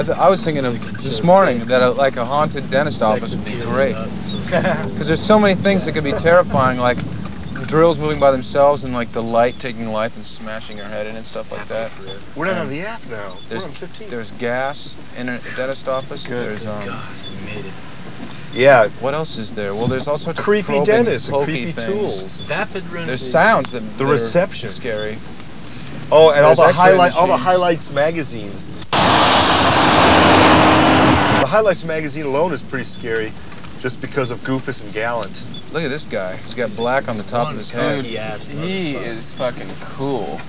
The band talks about haunted dentists' offices 1,786,262